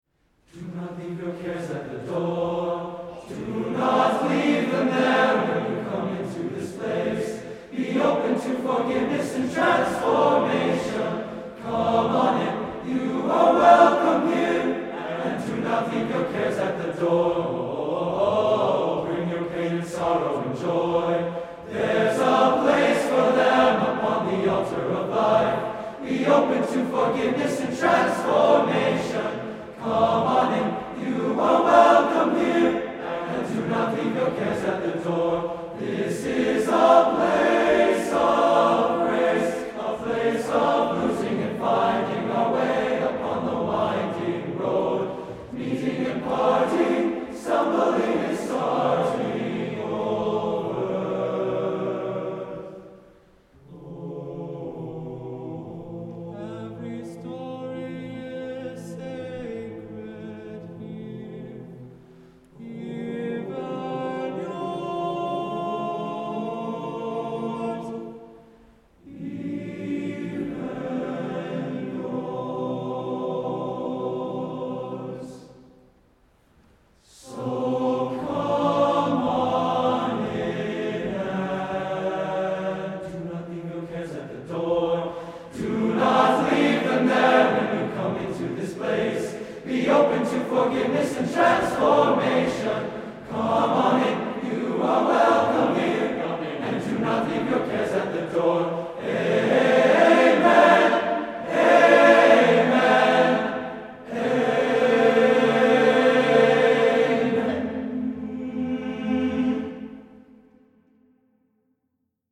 A feast of old-time gospel offering welcome and acceptance.
TBB a cappella